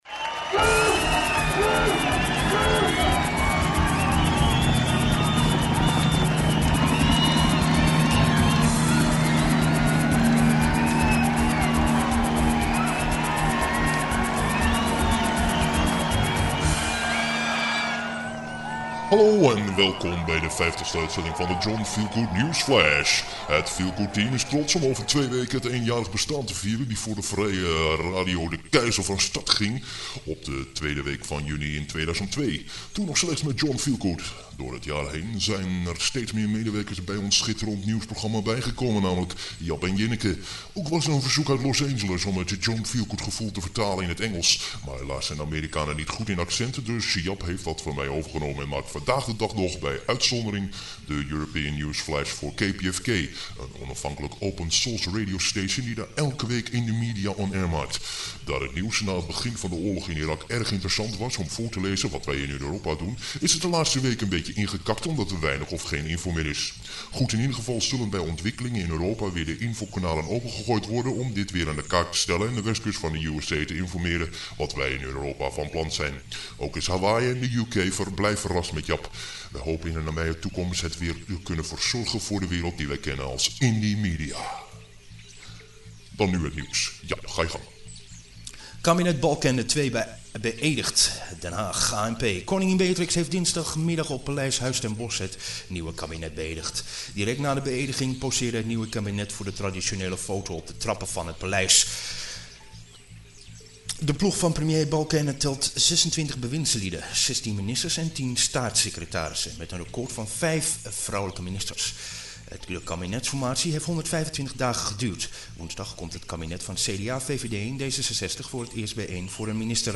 (er zitten een paar leesfoutjes in, maar dat komt door de feestvreugde in de studio)